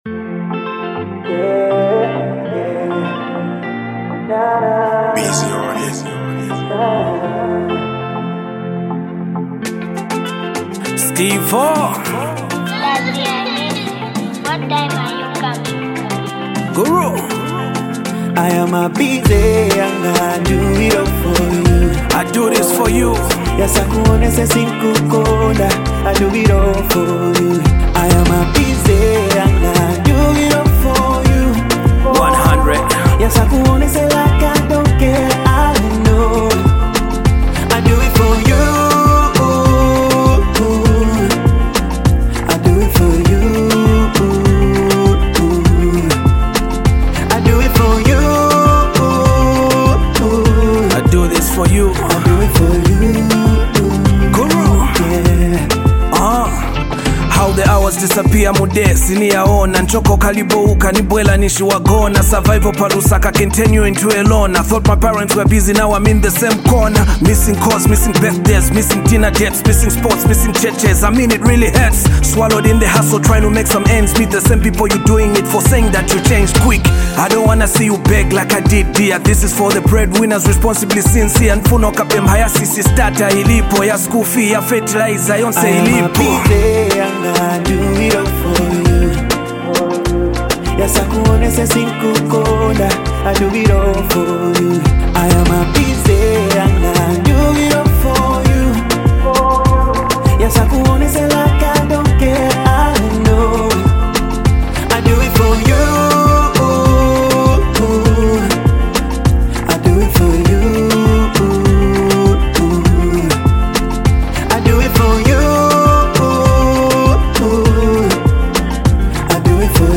soulful vocals
With its warm melodies and heartfelt lyrics